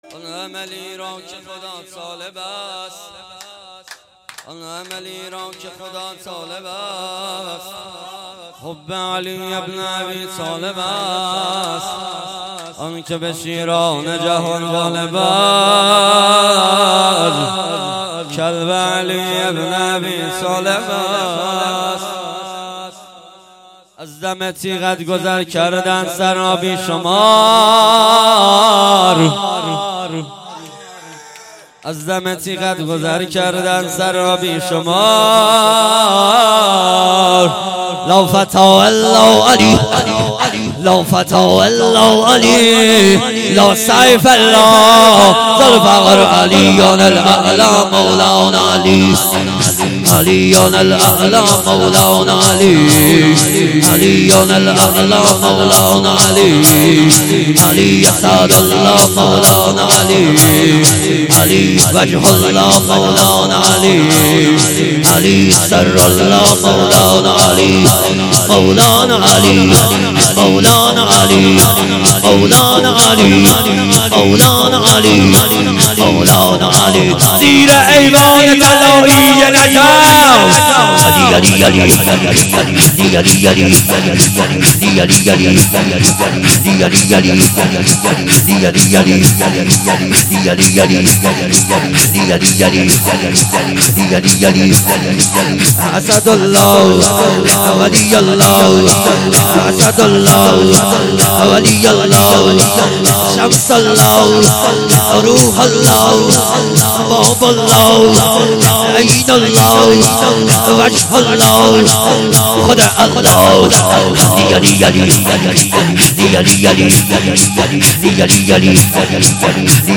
رجز-4.mp3